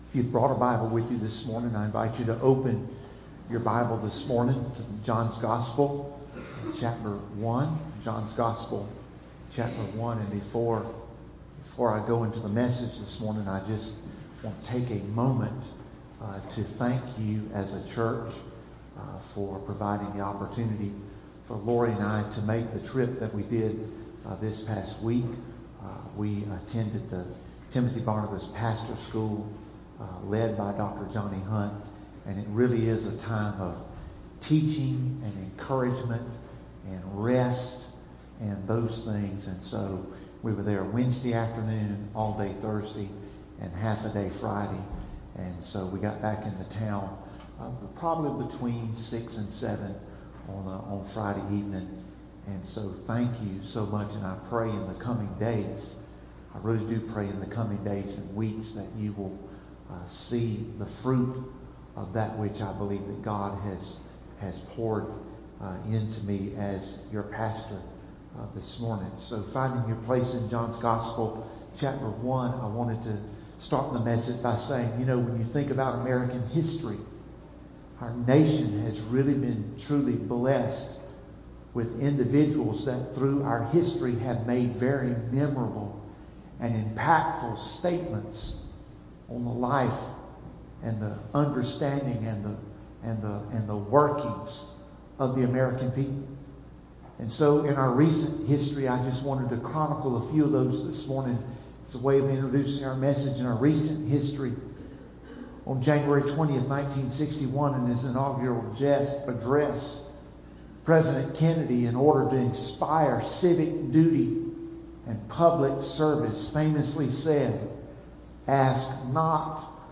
3-8-20-Website-Sermon-CD.mp3